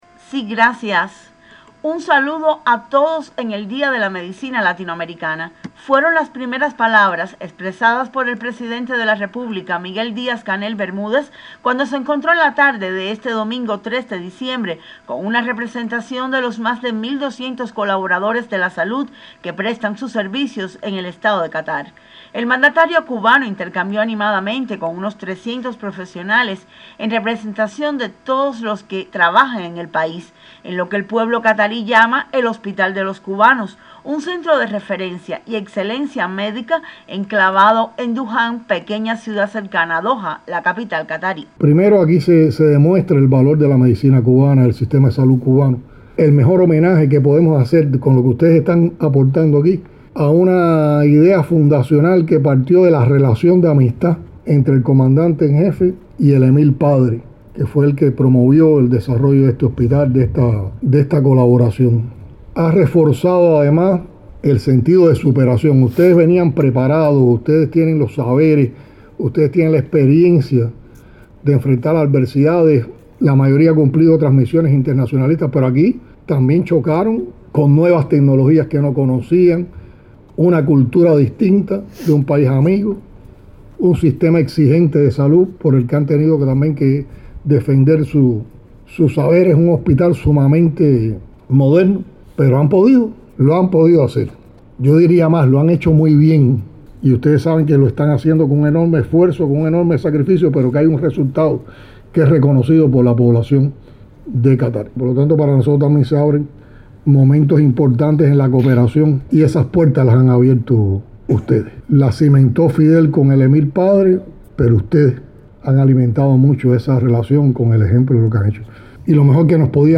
Este domingo en la tarde (hora local), el Primer Secretario del Comité Central del Partido Comunista y Presidente de la República de Cuba, Miguel Díaz-Canel Bermúdez, visitó el “Hospital de los cubanos” -enclavado en Dukhan, pequeña ciudad cercana a Doha, en Qatar-. En ese centro de referencia, donde los médicos de la Mayor de las Antillas han demostrado sus competencias, el dignatario felicitó a los colaboradores en el Día de la Medicina Latinoamericana.